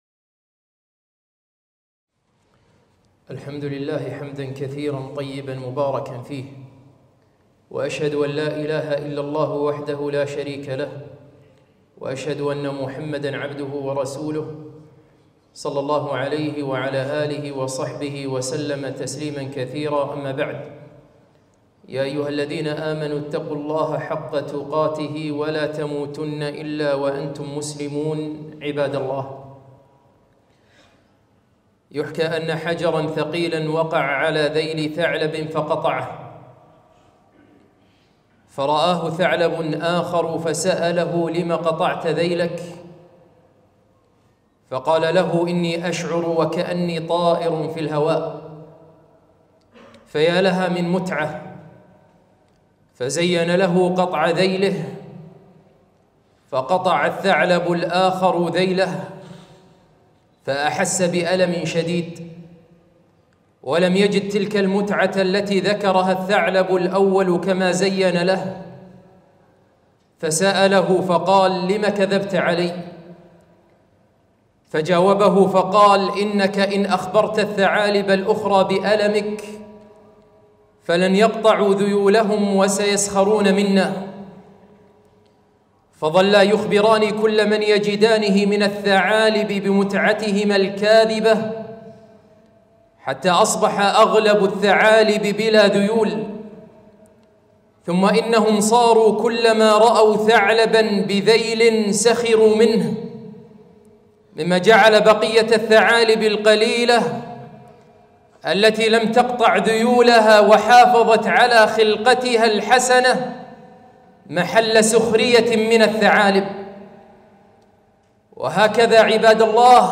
خطبة - ذيول الثعالب